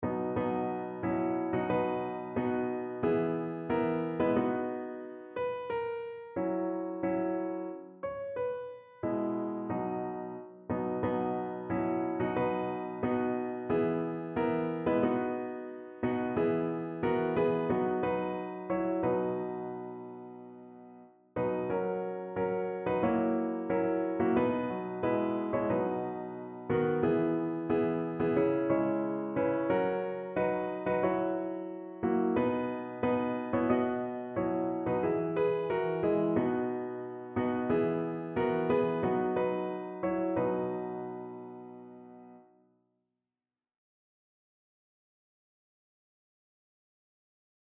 Evangeliumslieder
Notensatz (4 Stimmen gemischt)